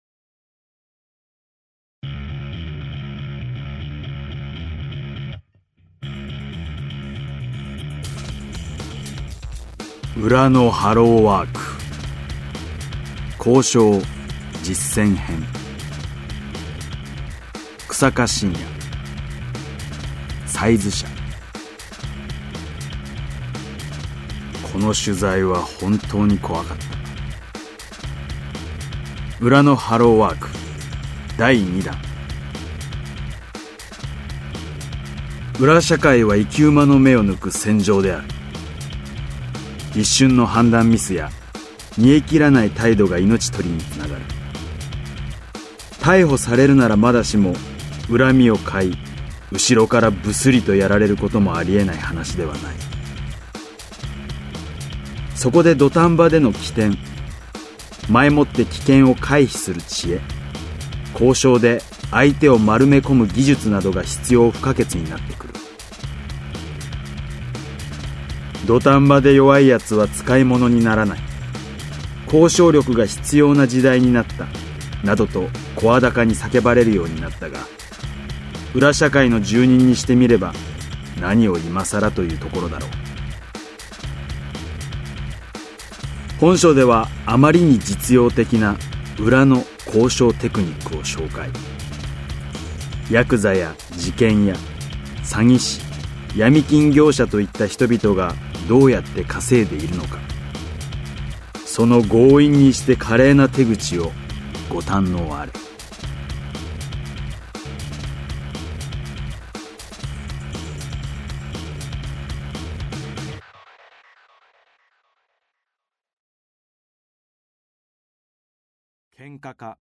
[オーディオブック] 裏のハローワーク「交渉・実践編」